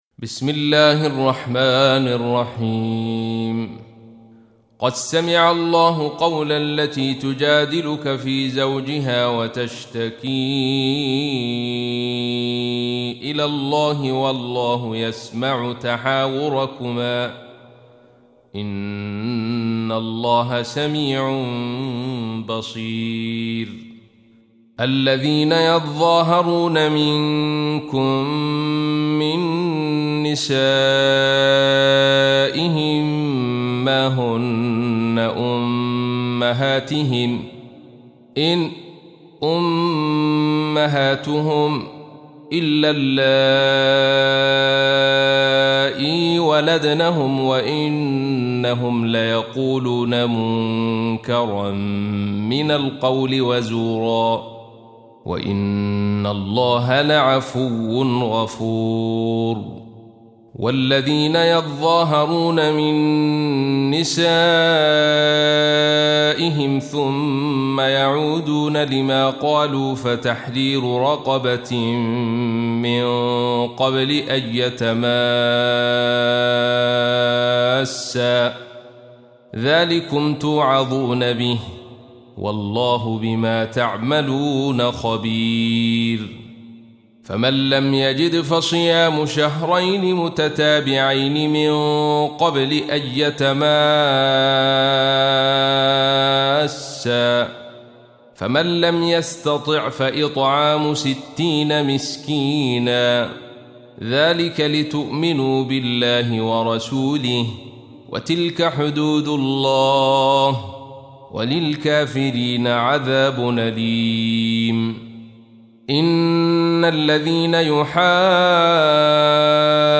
Surah Repeating تكرار السورة Download Surah حمّل السورة Reciting Murattalah Audio for 58. Surah Al-Muj�dilah سورة المجادلة N.B *Surah Includes Al-Basmalah Reciters Sequents تتابع التلاوات Reciters Repeats تكرار التلاوات